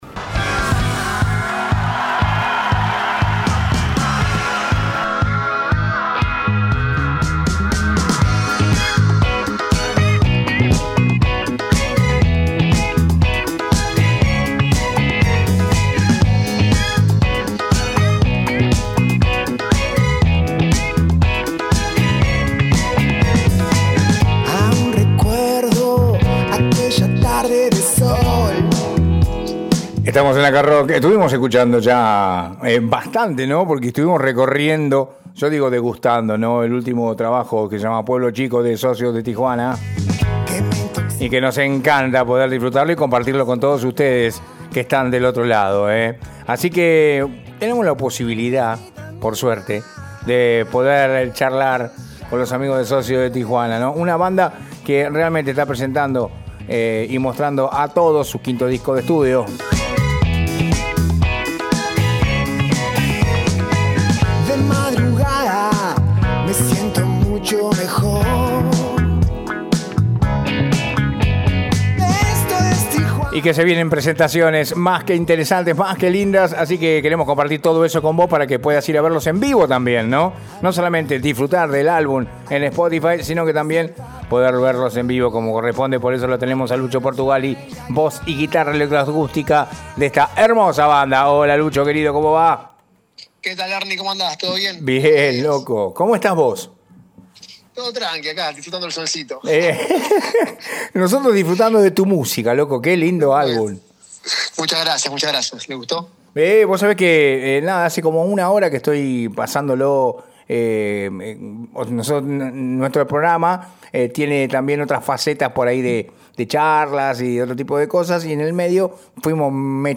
en Acá rock por Radio Cristal 94.9 y te mostramos la nota